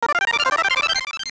サンプリングしたものや他の方から提供されたものではない，全てシンセで新規作成したデータですので，著作権の心配なく自由に使用できます。
（効果音作成に使用したシンセ。　YAMAHA V50,TG-500,SY99。その他エフェクター類）